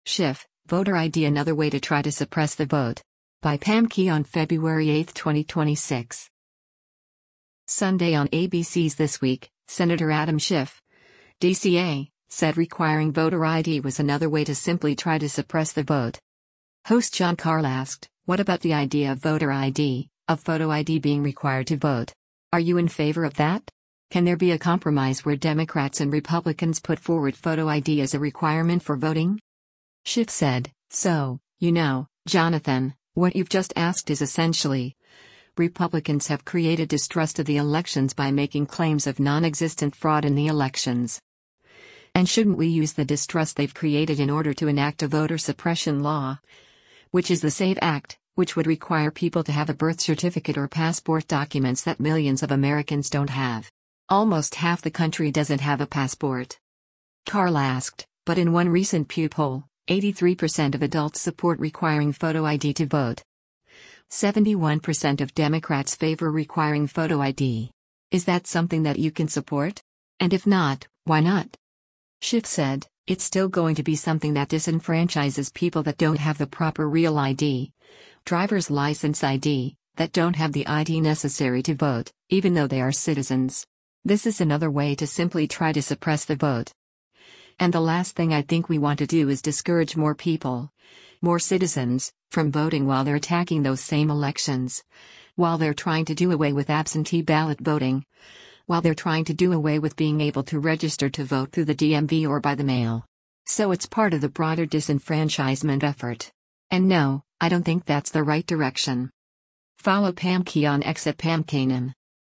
Sunday on ABC’s “This Week,” Sen. Adam Schiff (D-CA) said requiring voter ID was “another way to simply try to suppress the vote.”